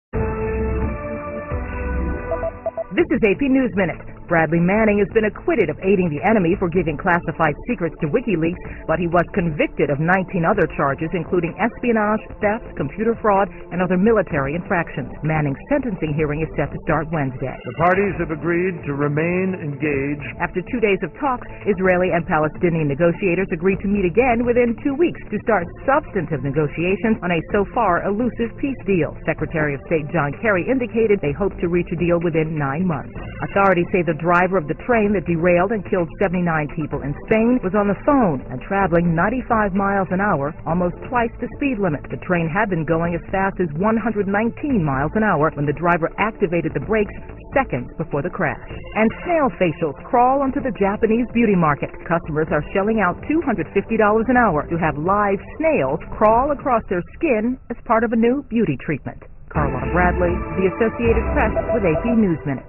在线英语听力室美联社新闻一分钟 AP 2013-08-03的听力文件下载,美联社新闻一分钟2013,英语听力,英语新闻,英语MP3 由美联社编辑的一分钟国际电视新闻，报道每天发生的重大国际事件。电视新闻片长一分钟，一般包括五个小段，简明扼要，语言规范，便于大家快速了解世界大事。